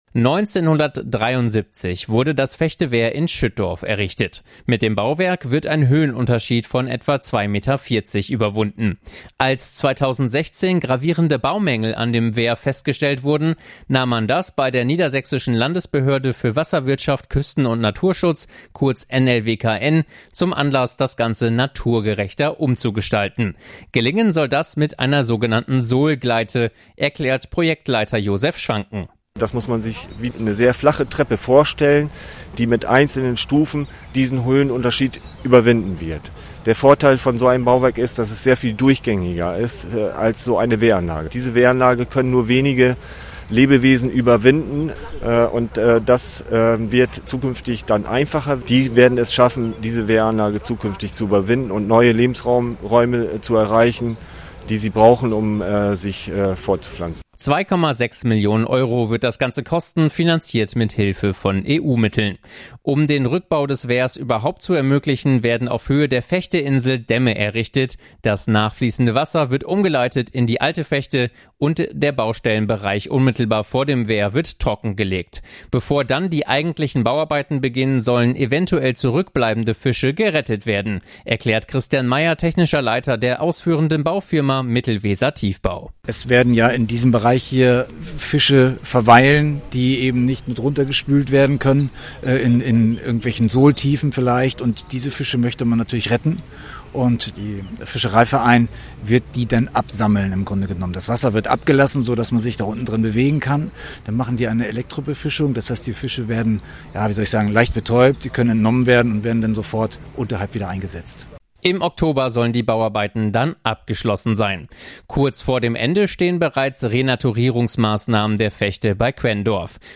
Zu beobachten ist das aktuell an zwei Projekten, die die Vechte in der Grafschaft Bentheim betreffen. EVW-Reporter